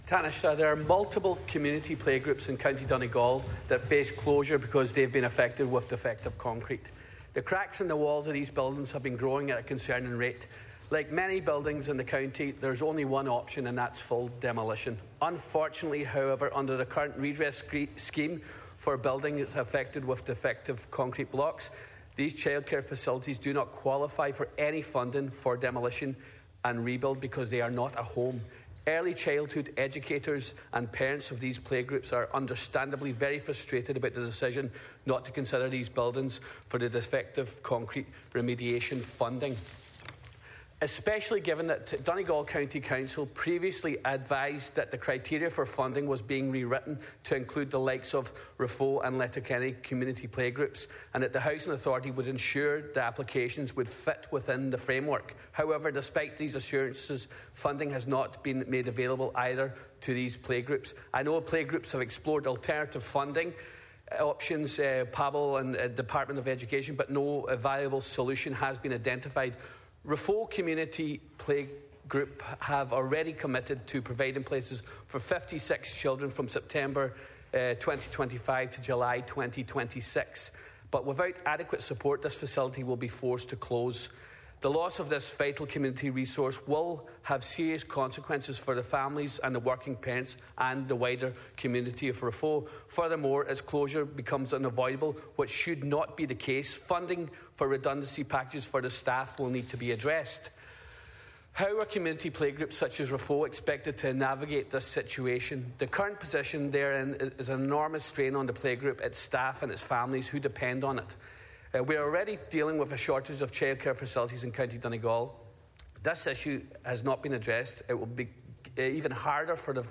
Donegal Deputy Charles Ward lobbied Simon Harris in the Dail to extend the current defective block scheme to include additional buildings such as; community and childcare centres.